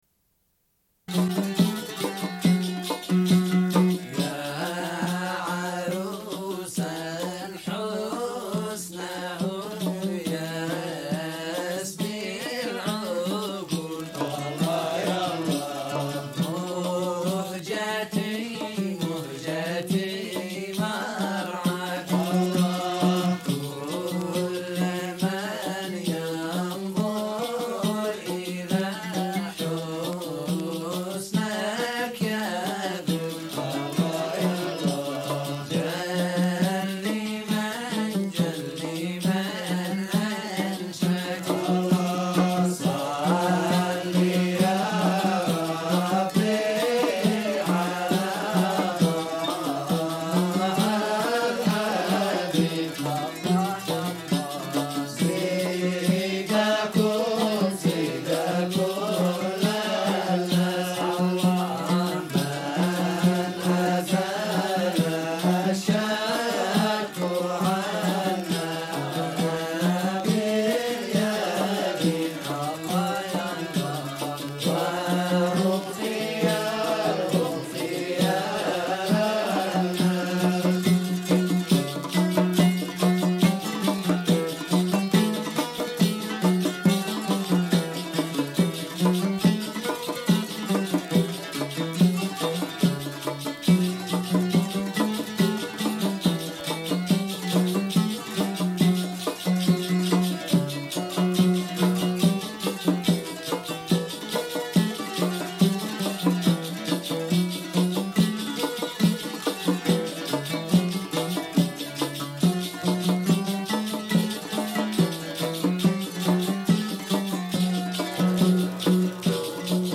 Une cassette audio, face B00:29:14